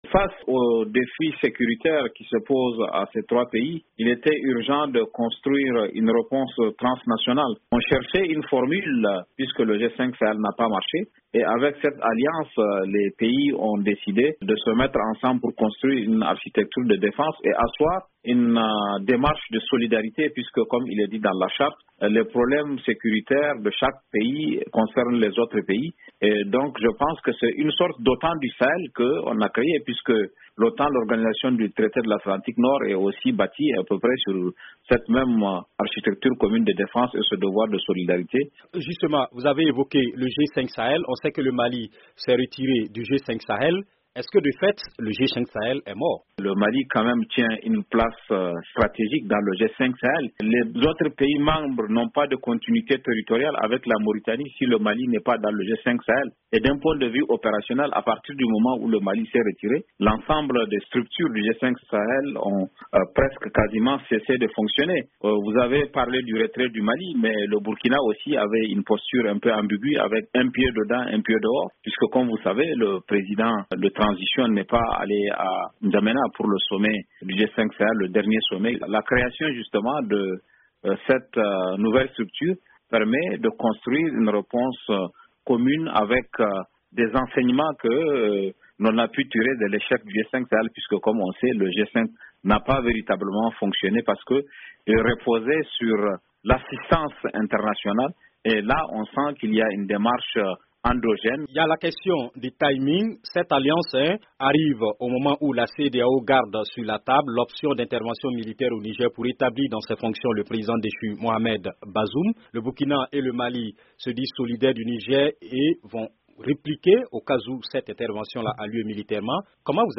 Naissance de l'Alliance des Etats du Sahel : l'analyse